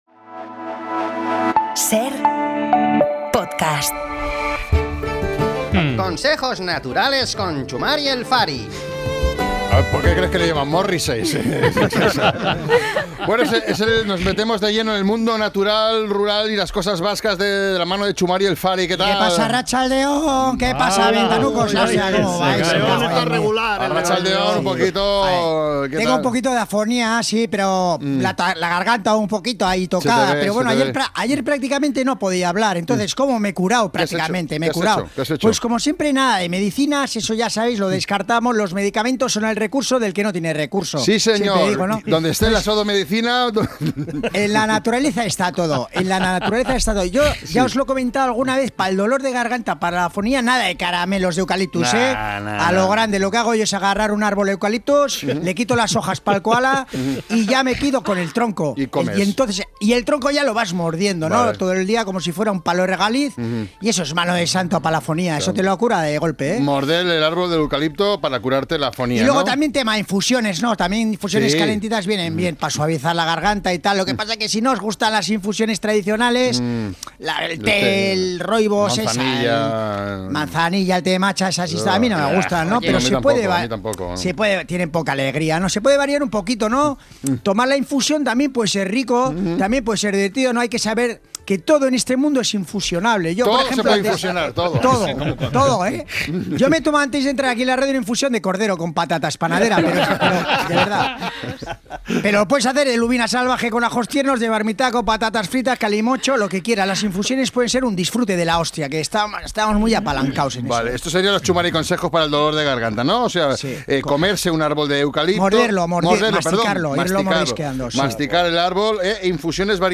sufre una ligera afonía